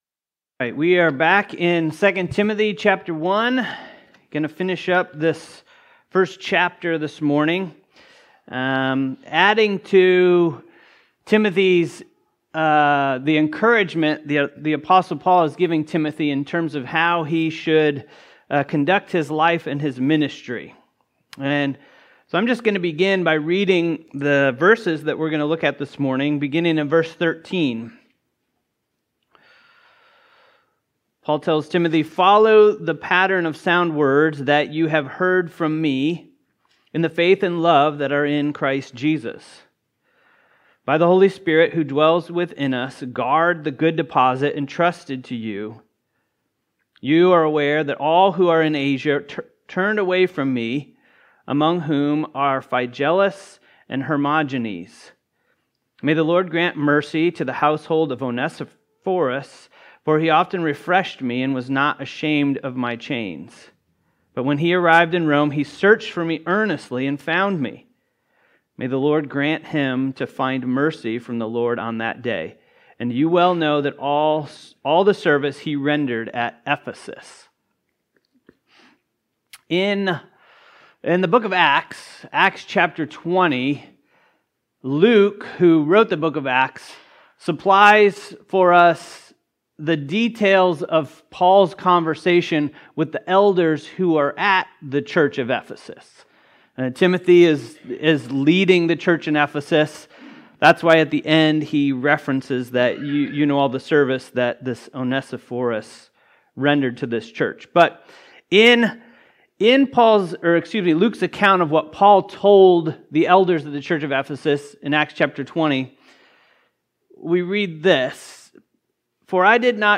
Guard the Good Deposit: Faithfulness in 2 Timothy 1 | Bible Sermon